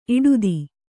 ♪ iḍudi